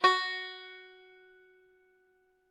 sound / instruments / banjo / Gb4.ogg
Gb4.ogg